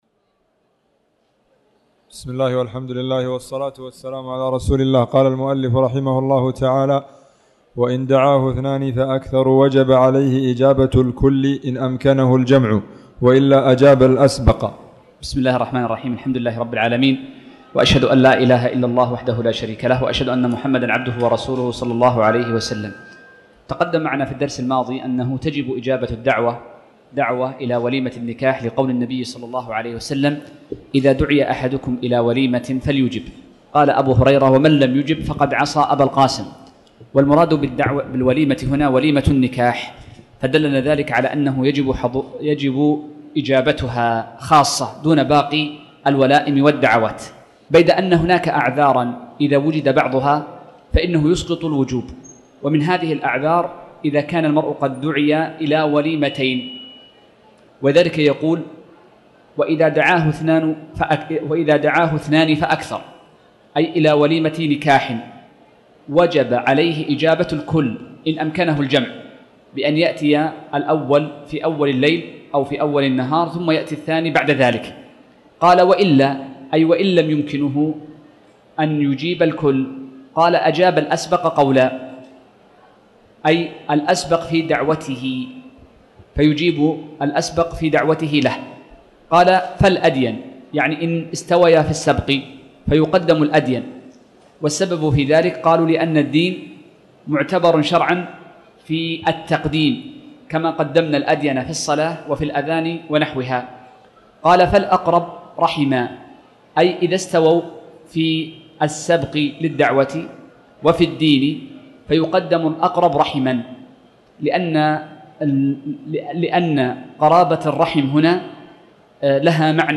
تاريخ النشر ١٣ ربيع الثاني ١٤٣٩ هـ المكان: المسجد الحرام الشيخ